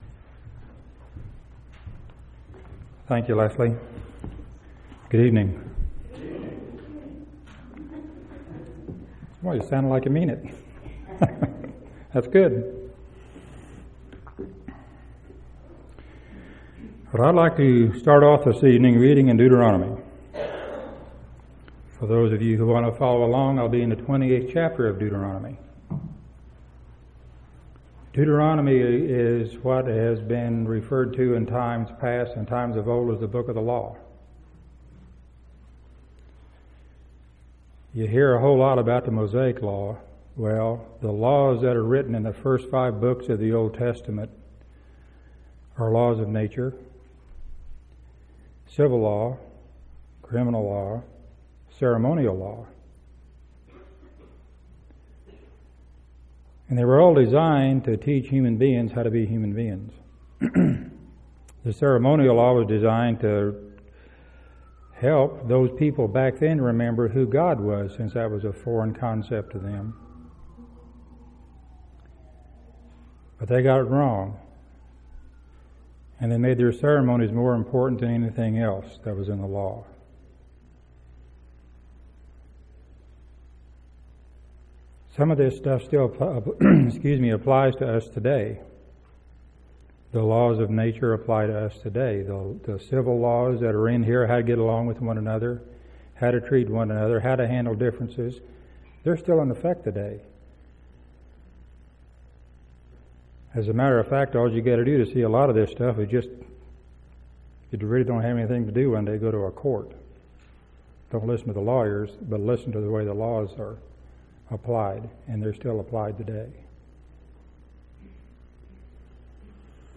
1/26/2003 Location: Temple Lot Local Event